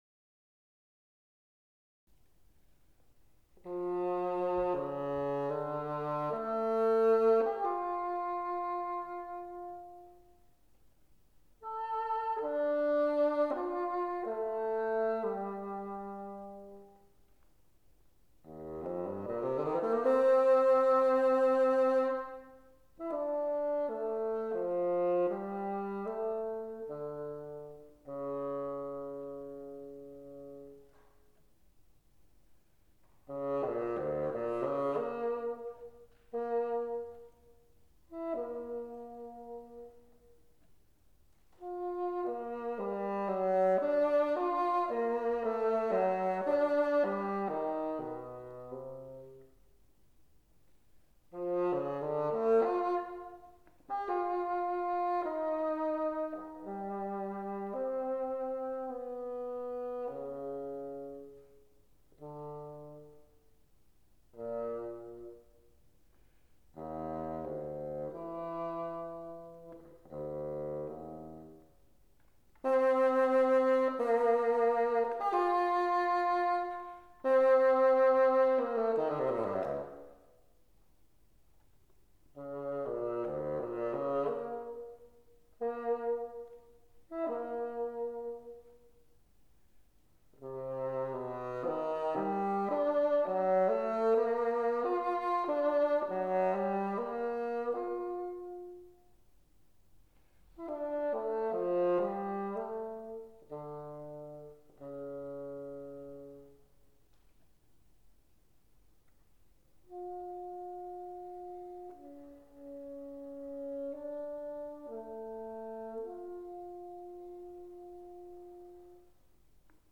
bassoon Duration